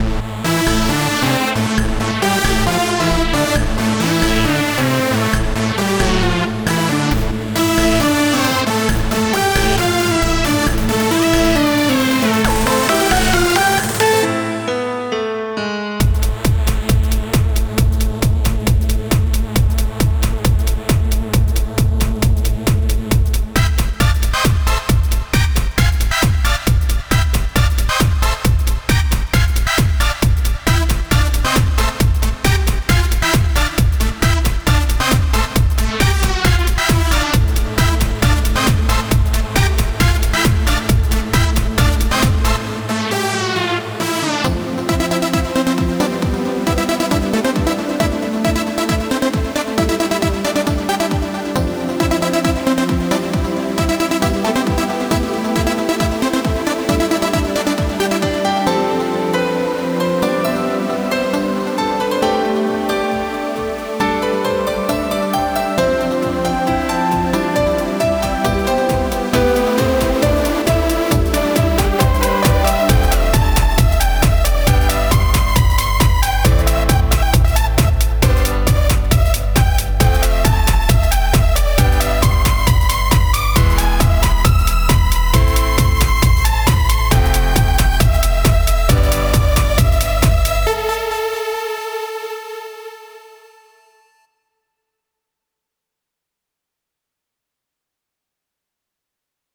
EDM作ってみたの巻
・ピアノ
・リードシンセ
・ドラム
・ベース
シンセはSAW系のバリっとした部分ほしいなぁ
ピアノで緩急つけたいな
ドラムのキックは４つ打ちでなおかつ生っぽさは出さない
ベースはにょろん(？）とした感じがいいかなー
１コーラス分のみの曲ですが良かったら聞いてやって下さい